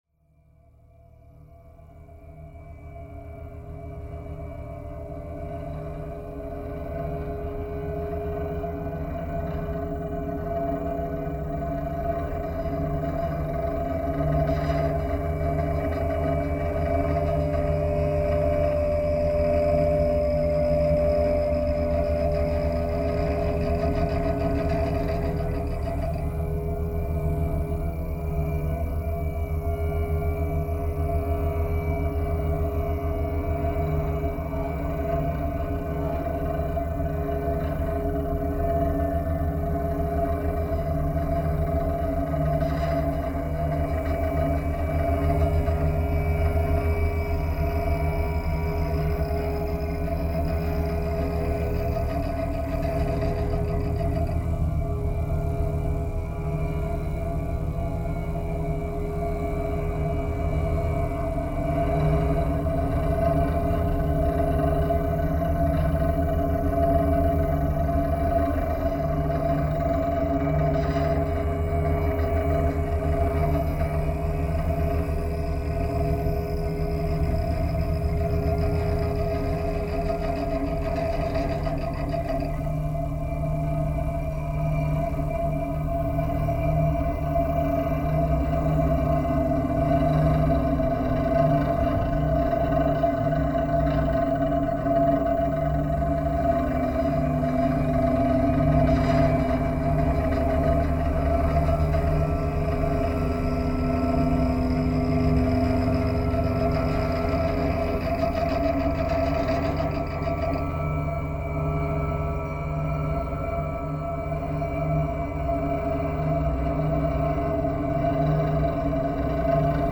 laptop, live electronics, real-time sampling, Max-Msp